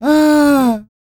Index of /90_sSampleCDs/ILIO - Vocal Planet VOL-3 - Jazz & FX/Partition I/2 LAUGHS